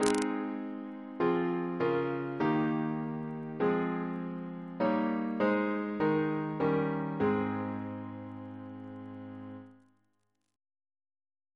Single chant in A♭ Composer: William H. Walter (1825-1893) Reference psalters: H1940: 608 685; H1982: S47